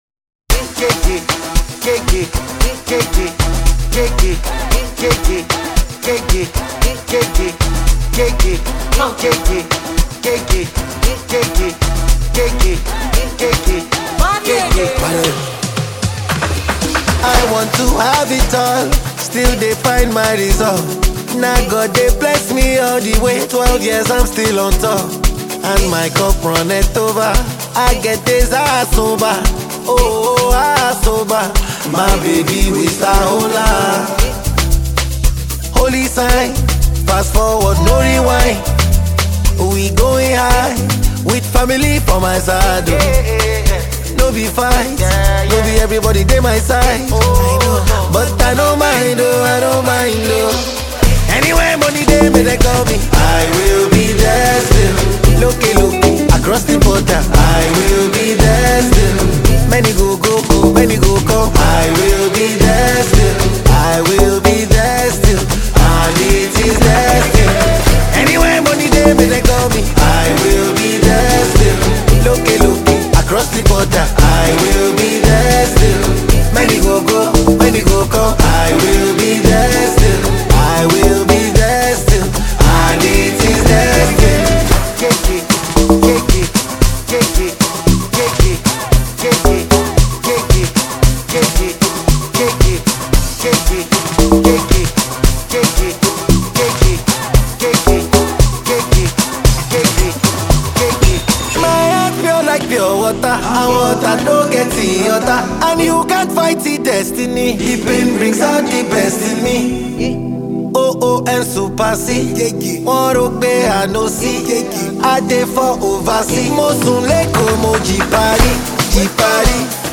This is an afrobeat song.